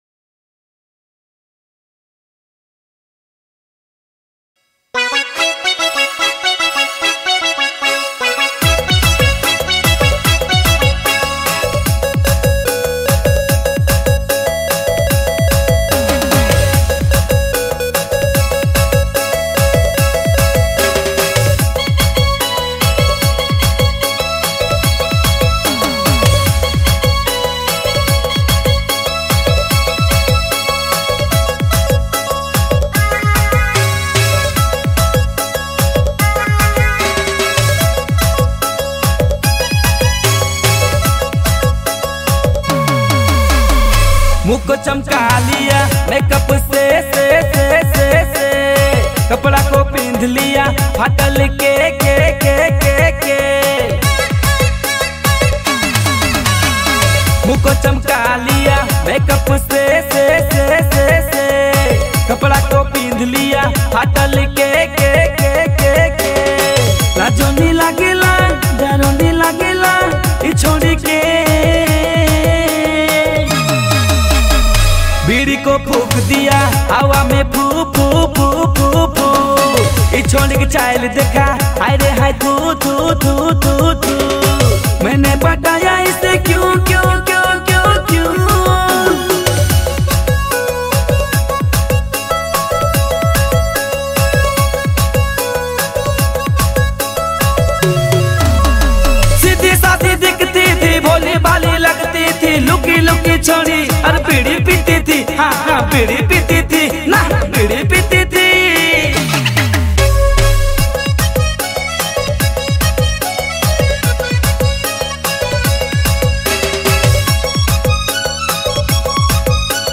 Nagpuri